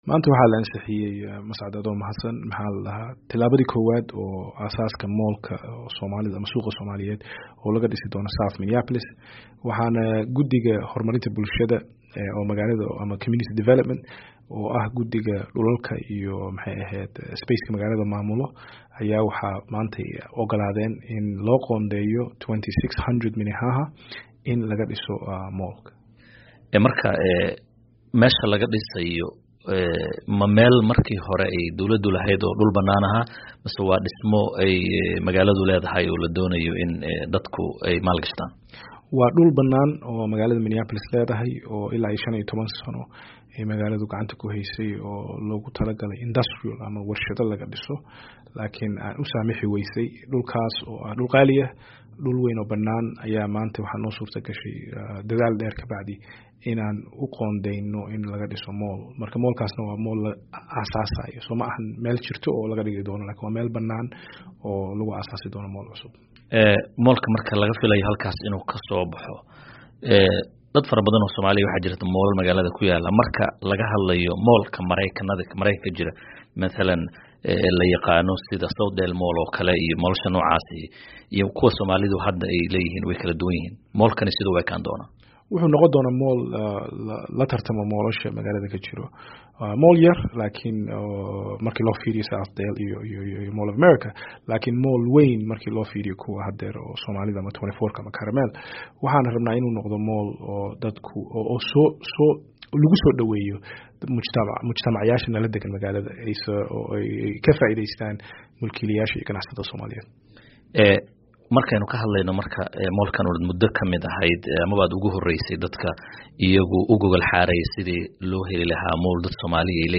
Halkaan ka dhageyso wareysiga Abdi Warsame